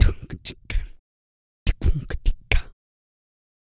EH REGGAE 1.wav